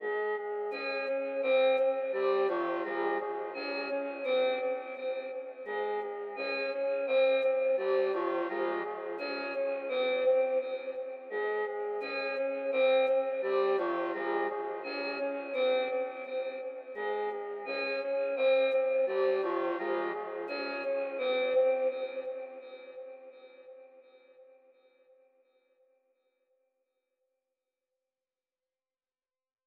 EN - Jungle (85 BPM).wav